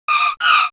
propellersScreetch.wav